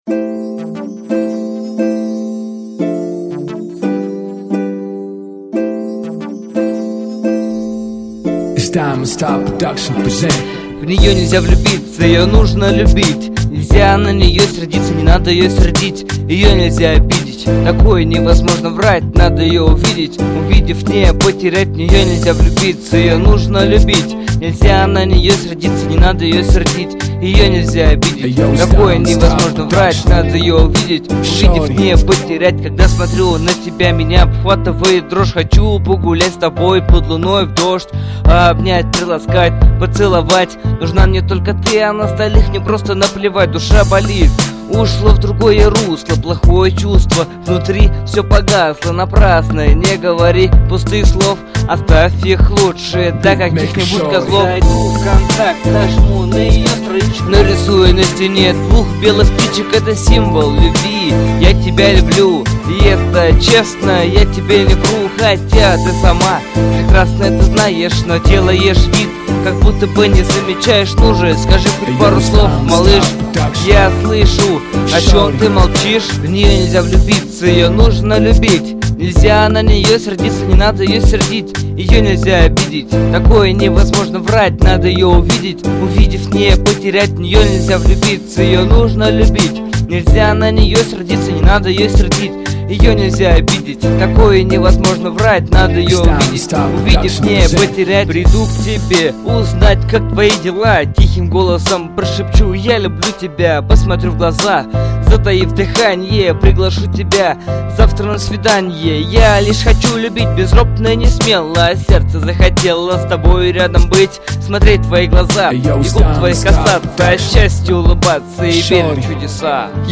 Жанр-рэп